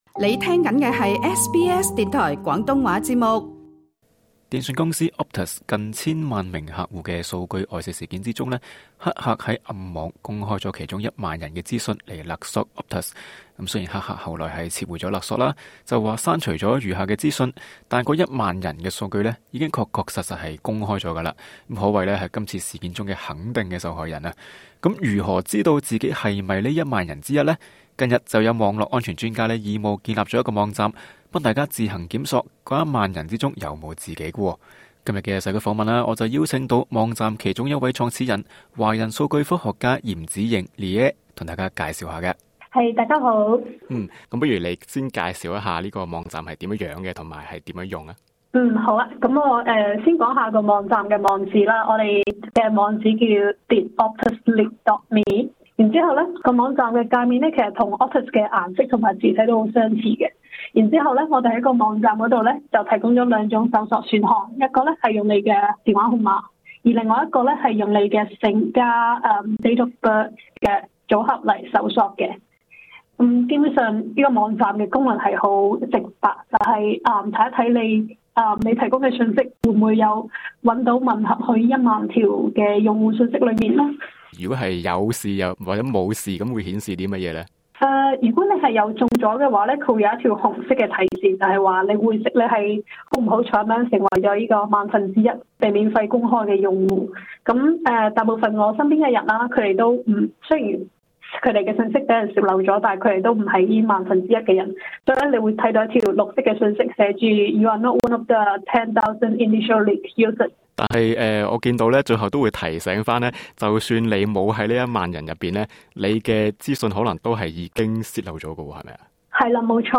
【社區訪問】